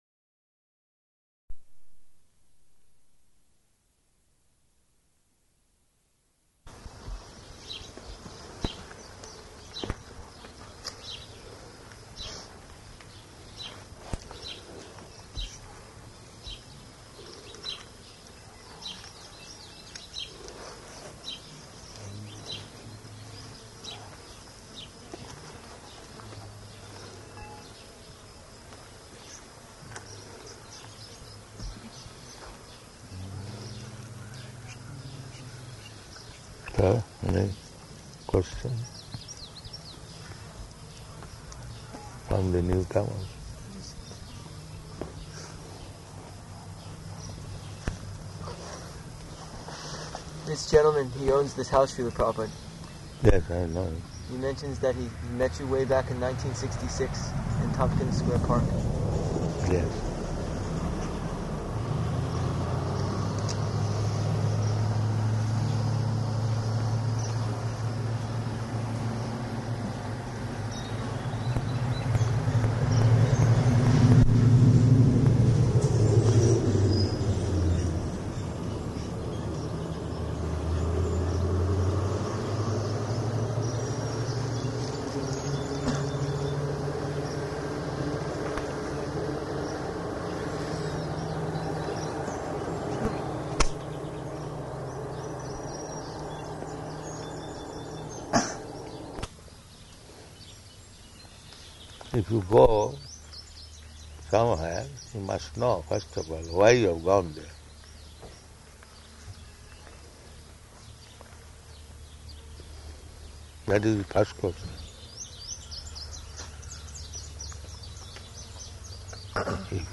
Garden Conversation --:-- --:-- Type: Conversation Dated: June 27th 1976 Location: New Vrindavan Audio file: 760627GC.NV.mp3 Prabhupāda: So, any question from the newcomers?